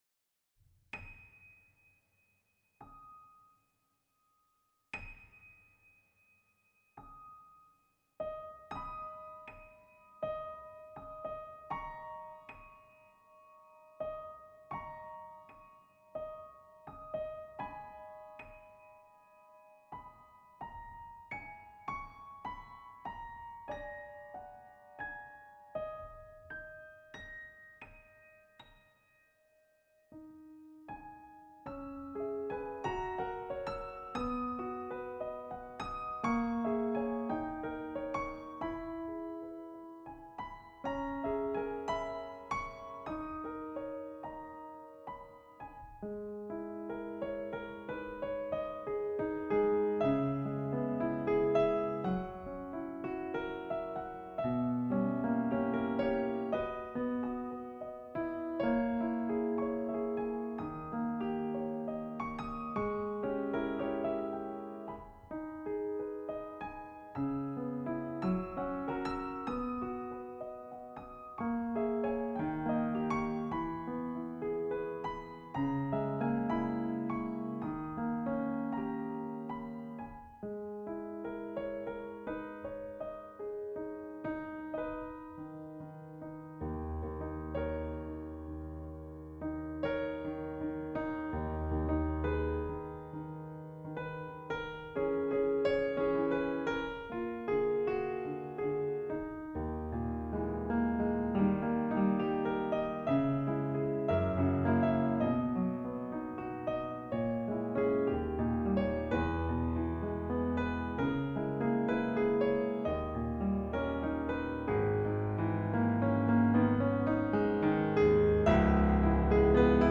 Небольшая импровизация
рояль
никакой обработки.